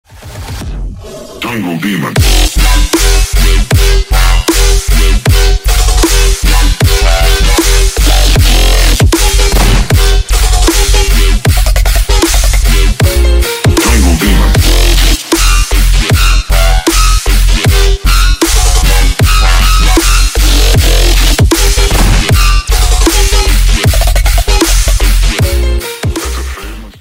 • Качество: 128, Stereo
громкие
dance
электронная музыка
Melodic dubstep
hard dubstep